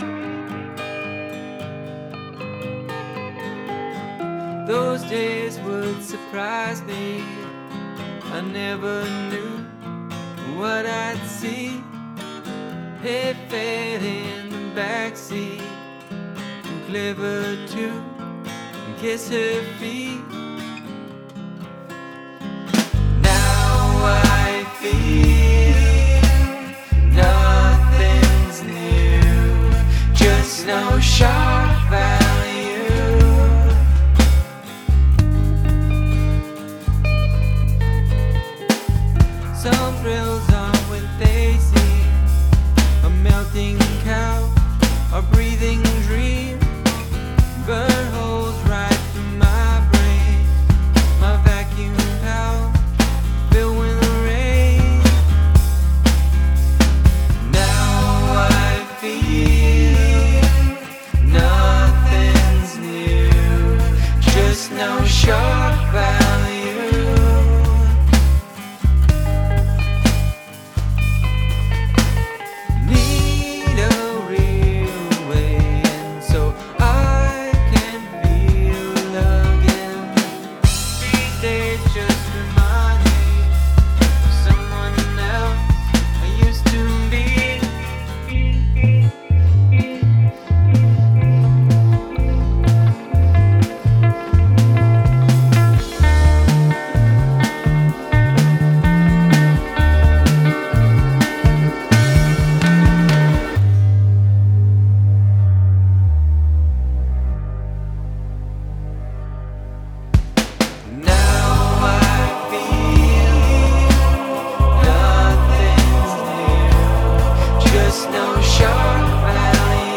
Really interesting vocal layering and FX.
Timing feels loose in places.